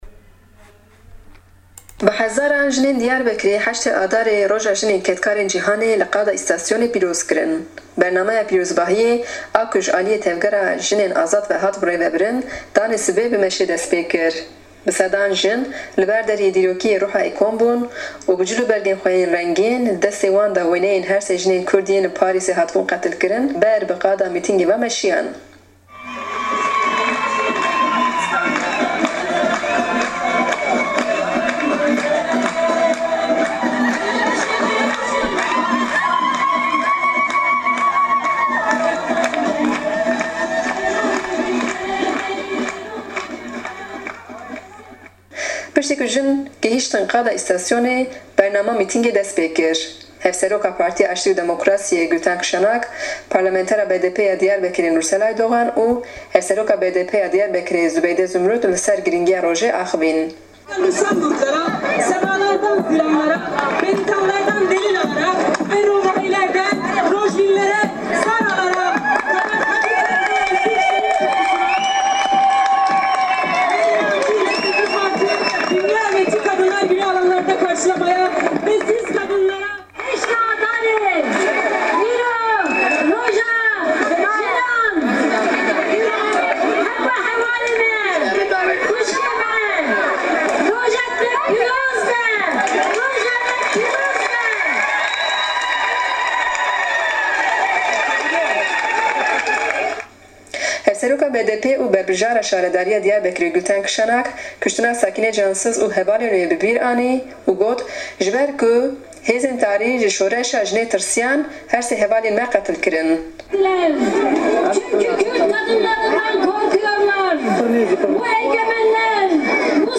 Raporta Diyarbekirê